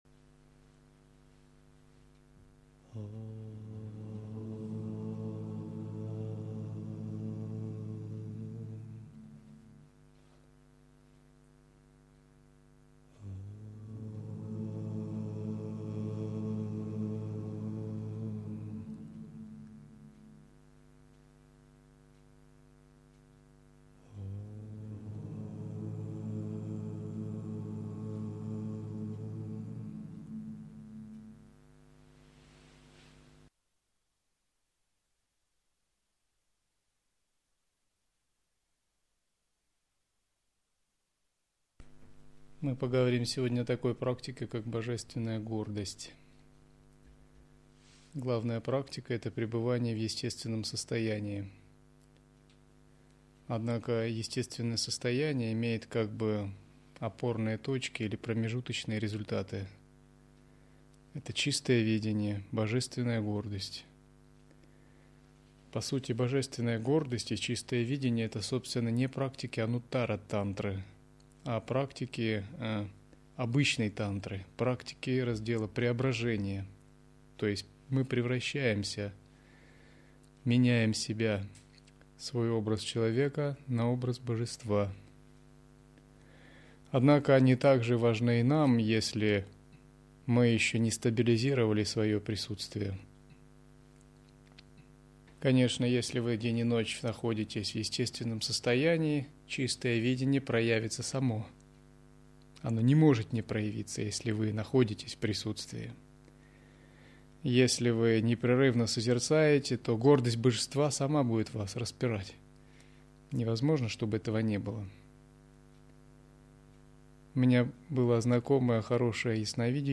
Лекции